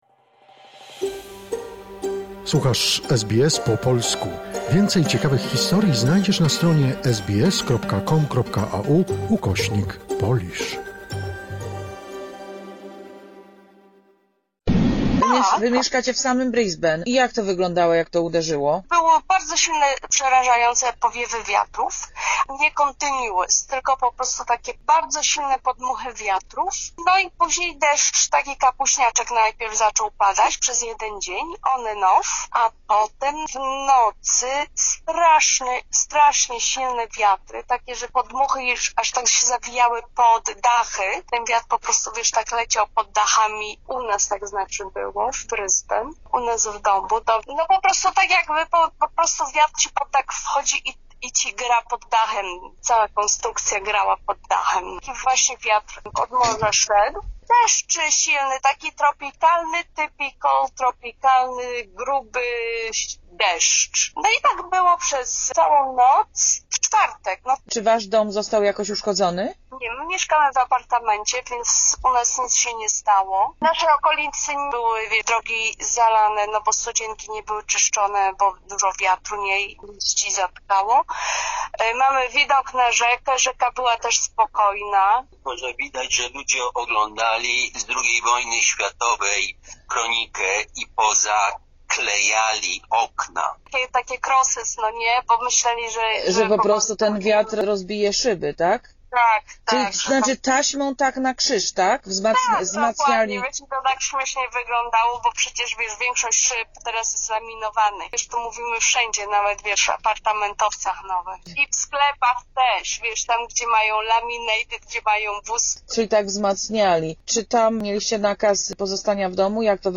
W sklepach brak chleba, mleka, jajek...okna pozaklejane taśmą..Polska para z Brisbane opowiada o ostatnich dniach w mieście...